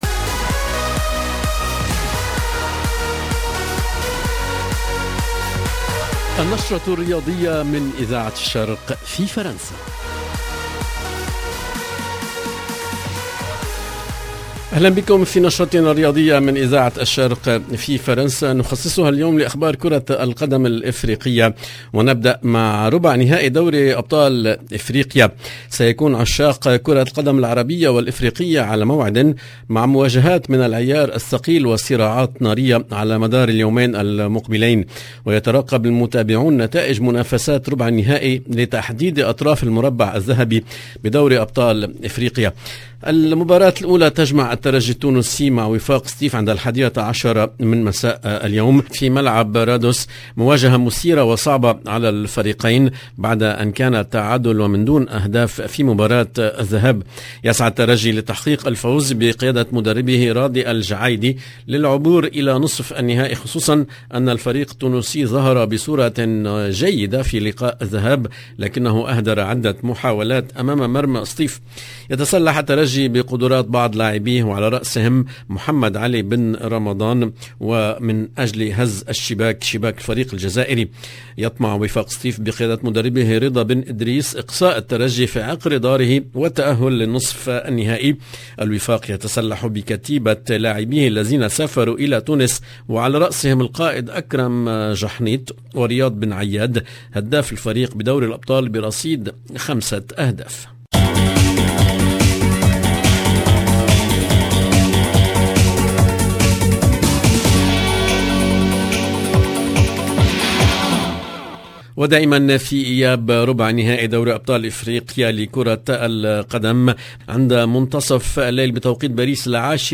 sport 22 avril 2022 - 11 min 30 sec Radio Orient sport LB RADIO ORIENT SPORT Dans notre journal sportif les rencontres des quarts de finales retour de la ligue des champions d’Afrique , des rencontres 100/100 Arabes entre des équipes du Maroc , Égypte, Tunisie et Algérie 0:00 11 min 30 sec